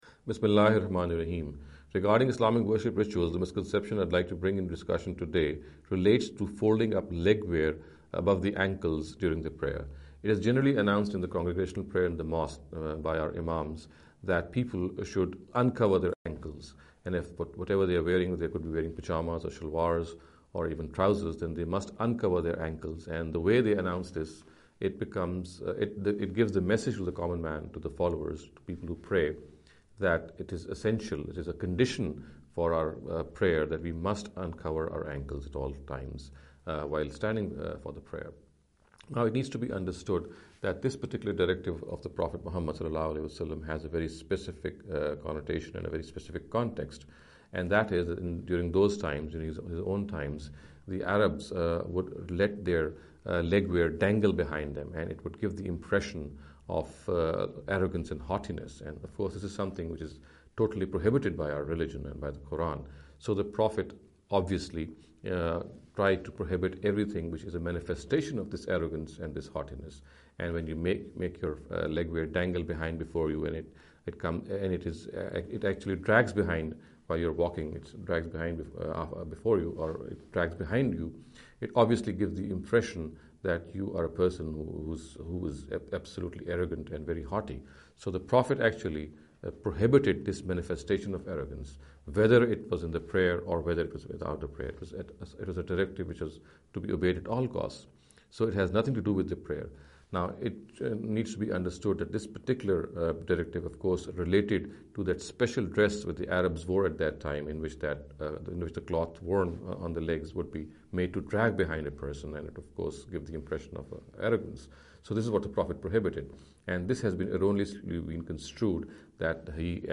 In the series of short talks “Islamic Worship Rituals